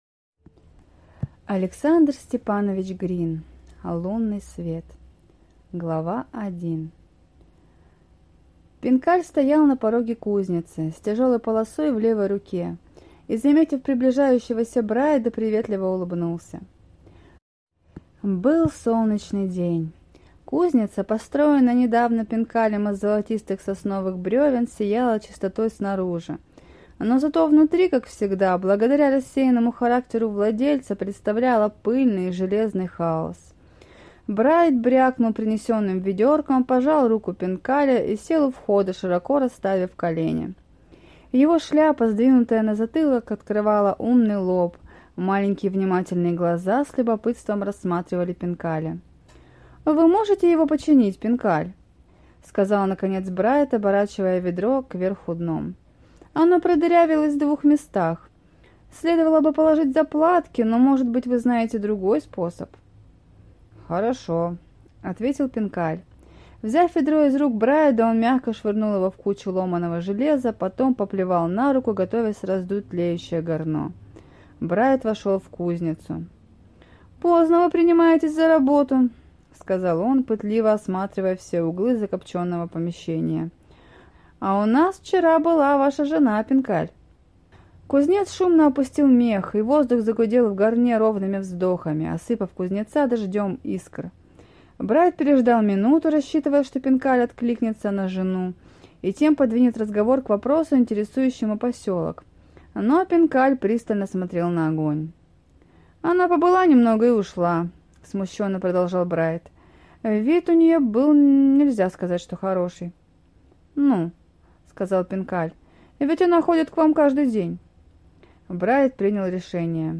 Аудиокнига Лунный свет | Библиотека аудиокниг